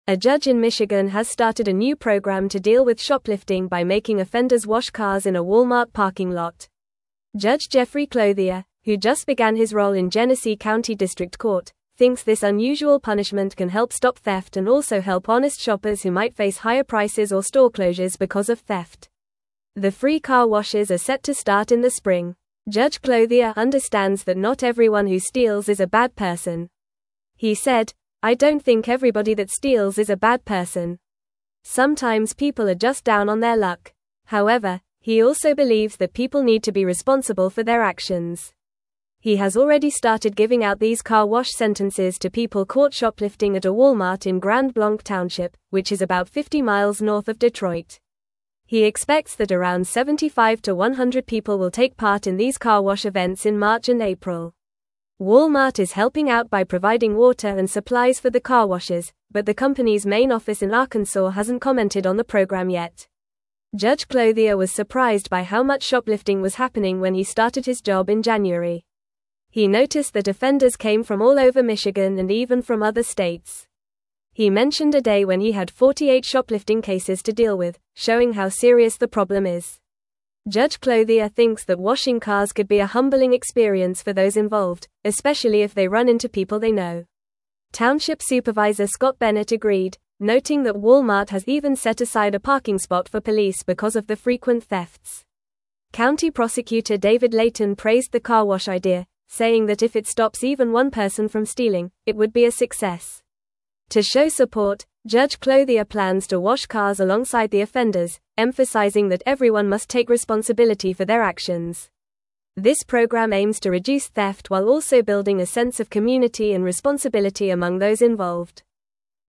Fast
English-Newsroom-Upper-Intermediate-FAST-Reading-Michigan-Judge-Introduces-Unique-Community-Service-for-Shoplifters.mp3